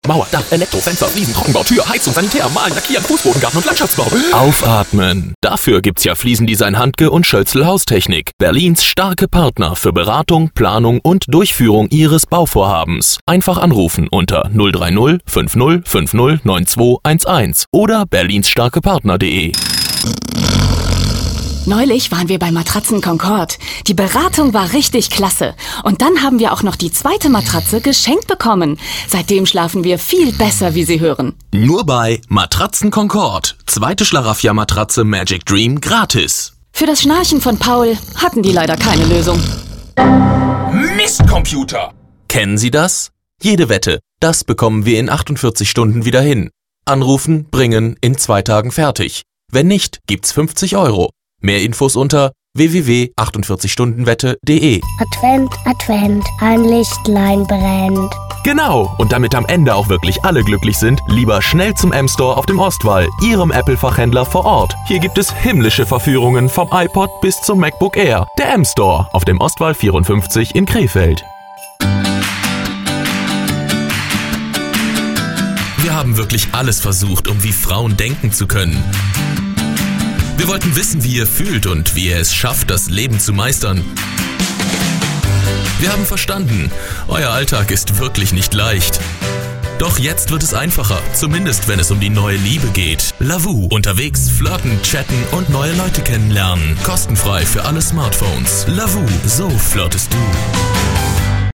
präsent, wandelbar, flexibel, spontan - Eigenes Studio mit ISDN, SourceConnect & Skype
Sprechprobe: Werbung (Muttersprache):